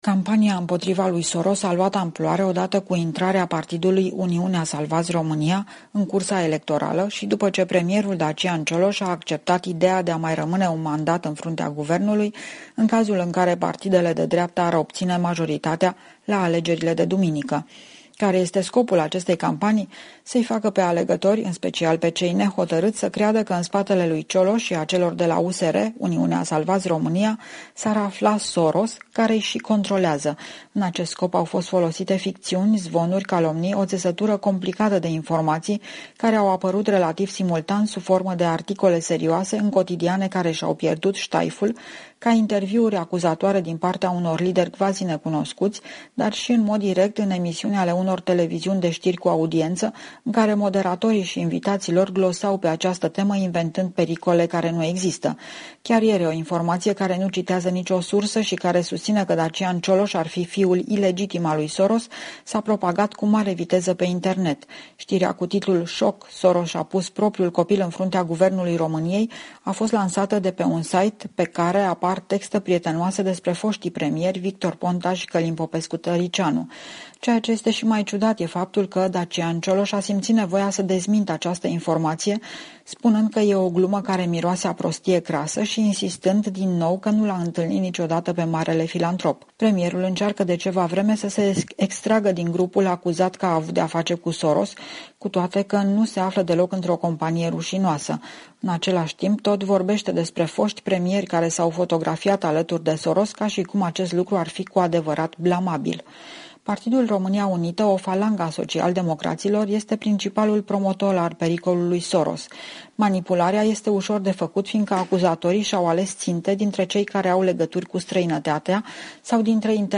Corespondența zilei de la București